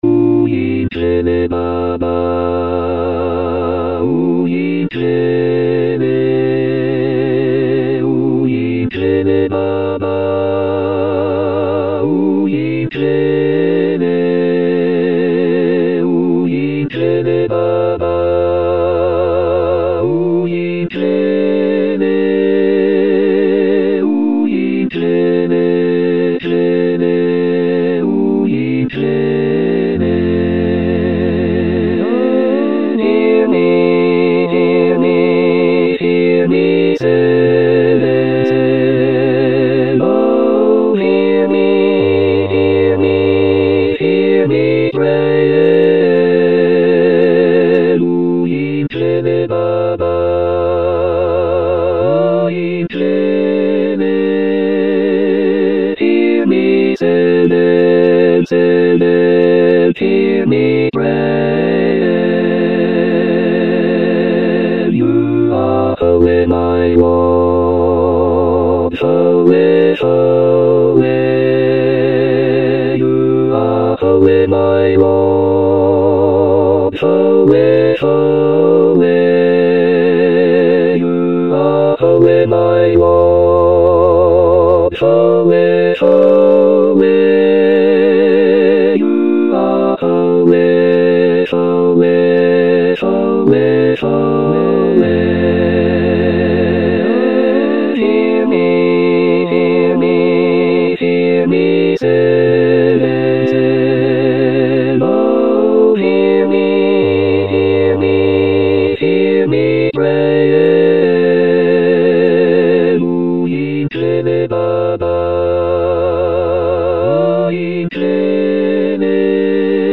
Uyingcwele_Tutti.mp3